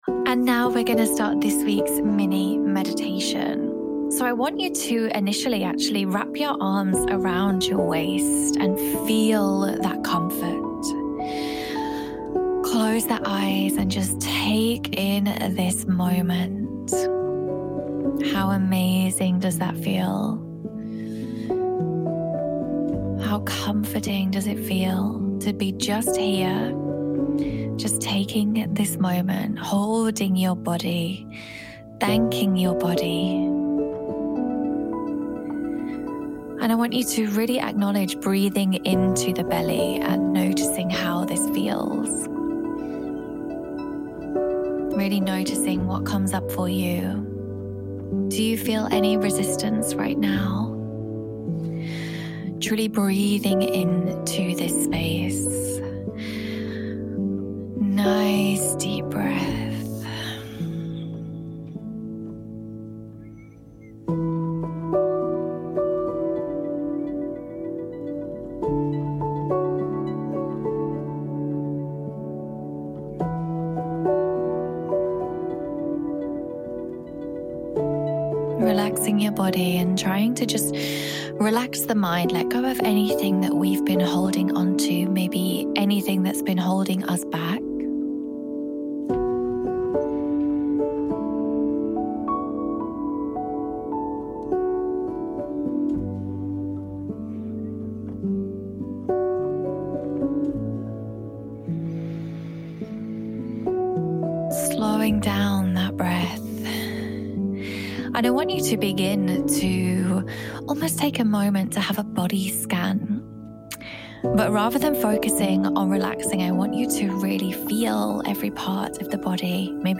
Mini Meditation to love your body